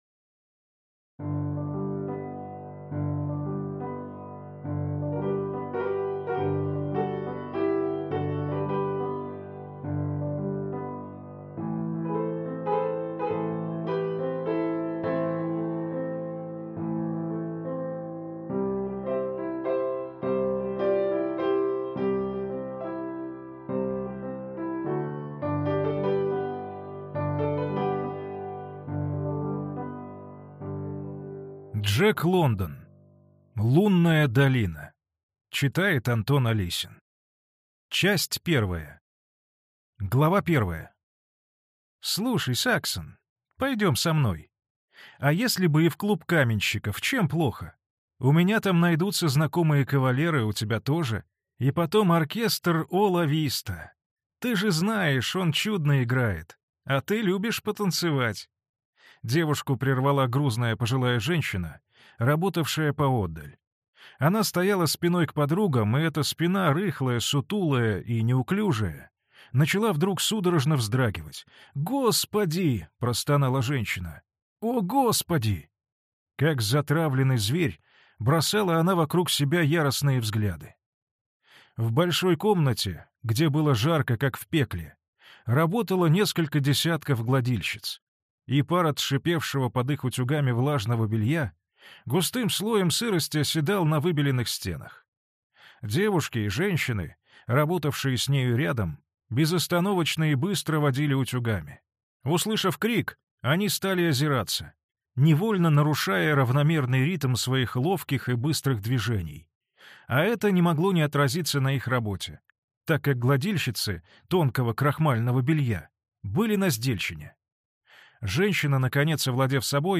Аудиокнига Лунная долина. Часть 1 | Библиотека аудиокниг